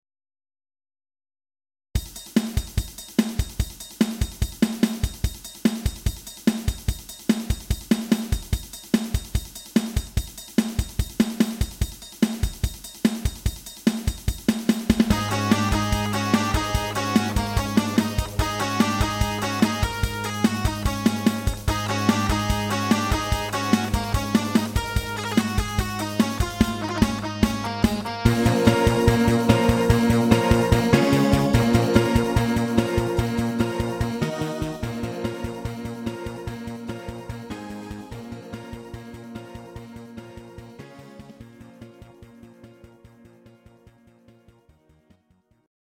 Greek Rock